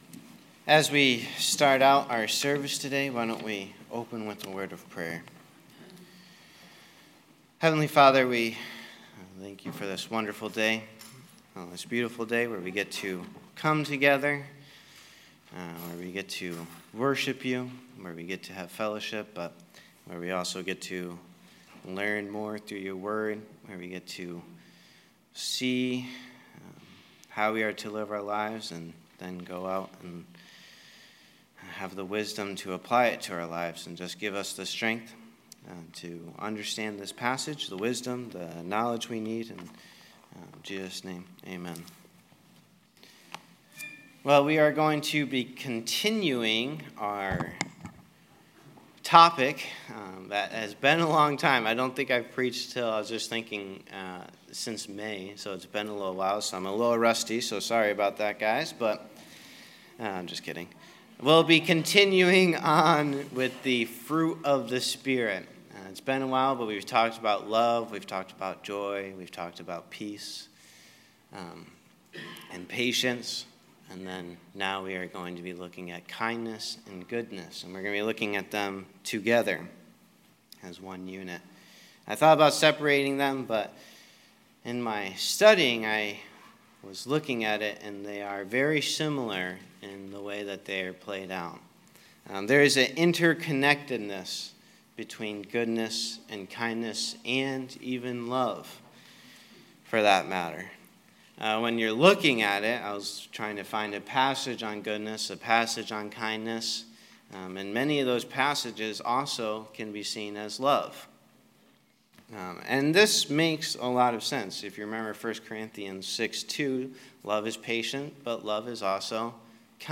Sermons | Ellington Baptist Church